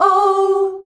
OUUH  G.wav